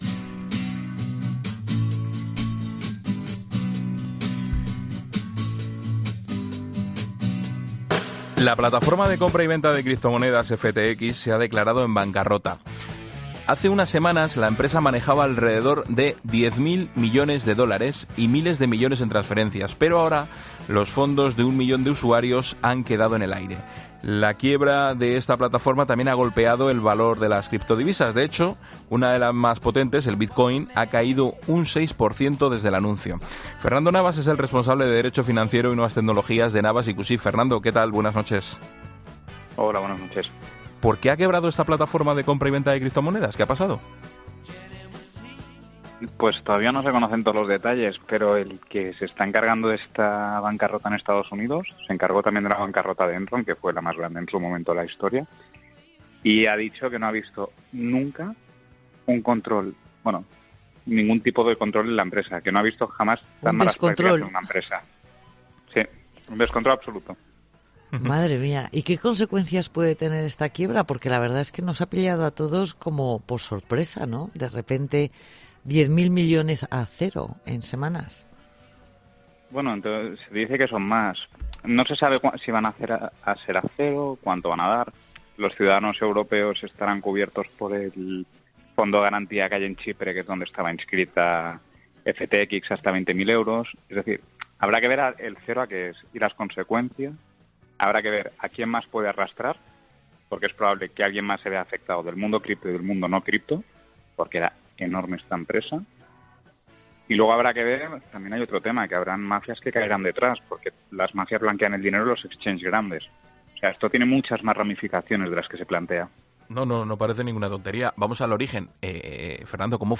Con Ángel Expósito